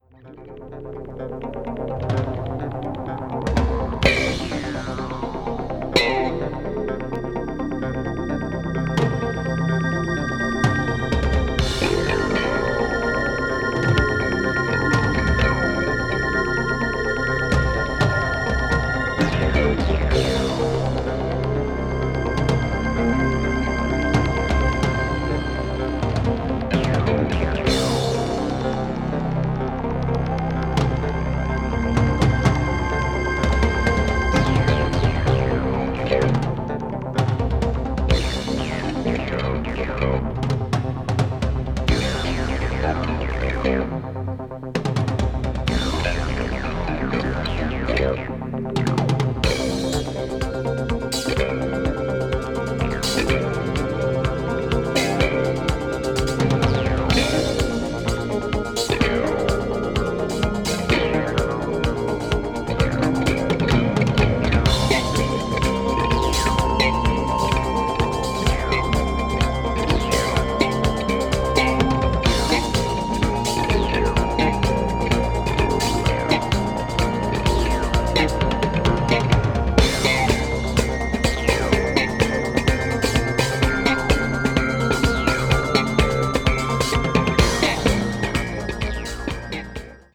electronic   progressive rock   symphonic rock   synthesizer